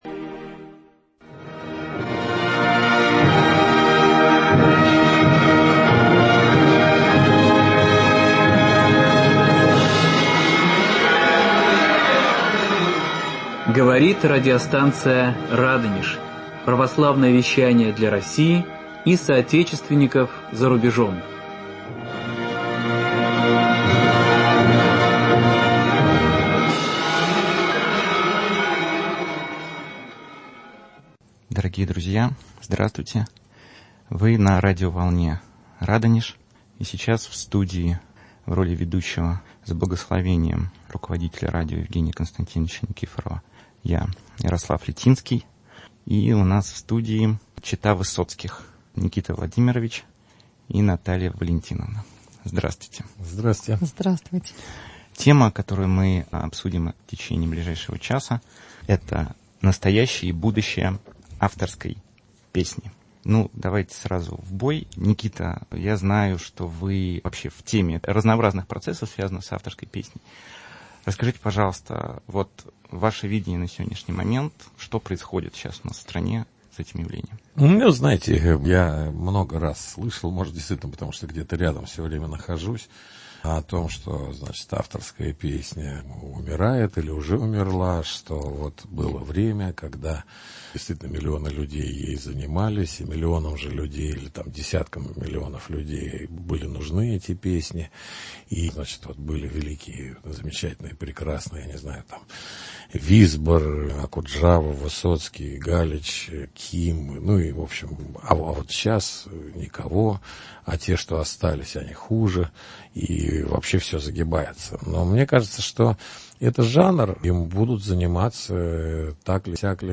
Звучат стихи и песни в исполнении наших гостей.